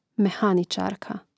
mehàničārka mehaničarka